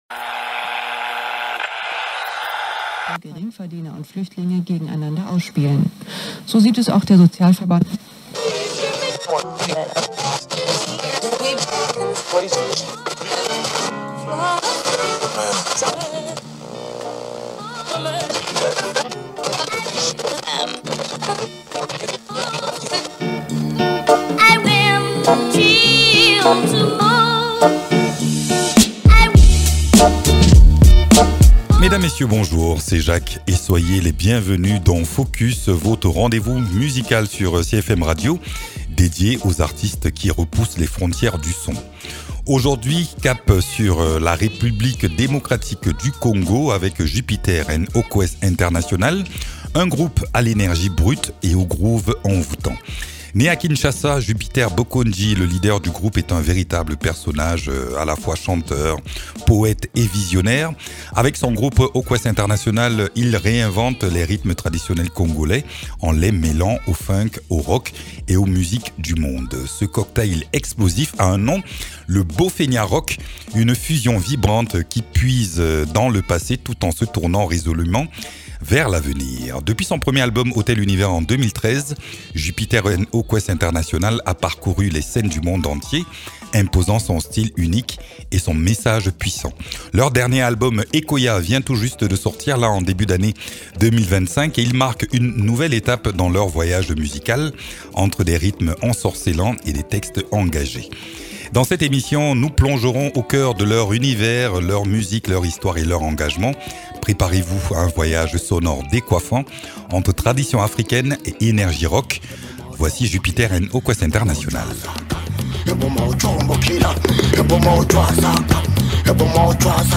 A la découverte de ce groupe qui bouscule les codes en mêlant rythmes traditionnels congolais, rock, funk et afrobeat.